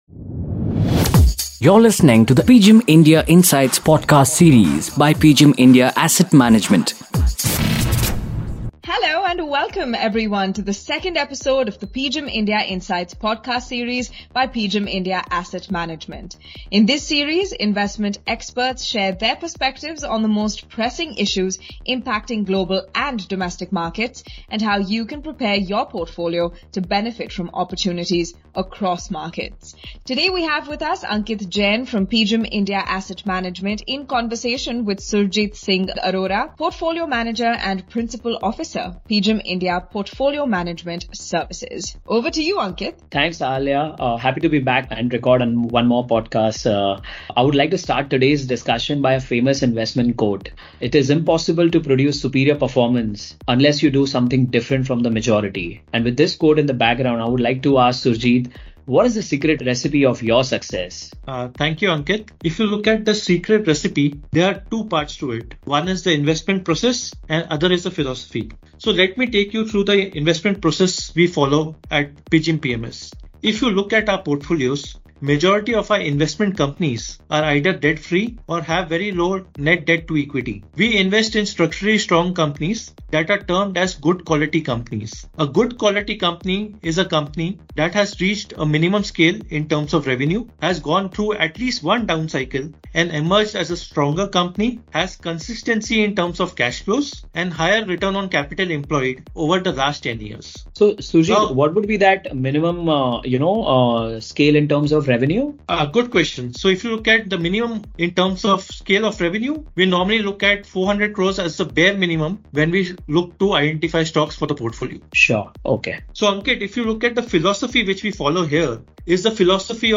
Listen to this conversation to understand his investment philosophy and why he is bullish on as Industrials, Autos, Real Estate and Building materials sectors.